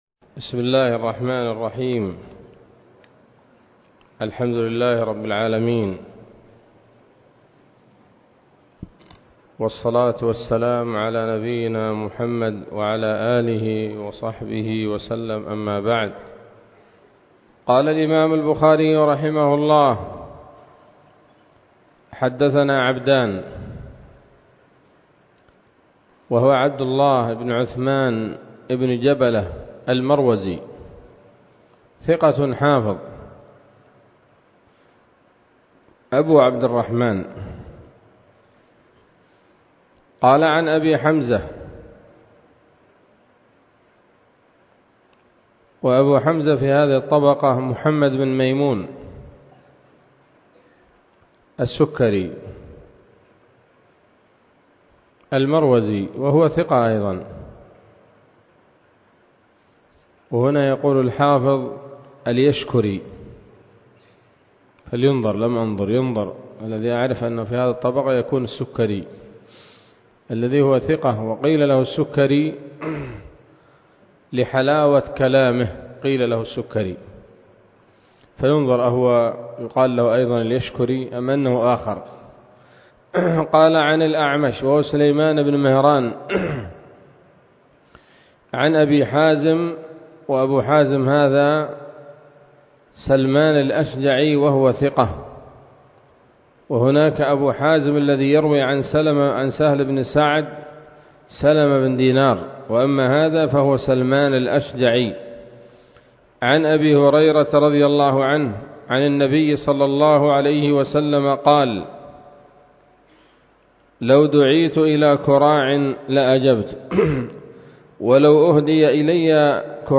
الدرس الثاني والستون من كتاب النكاح من صحيح الإمام البخاري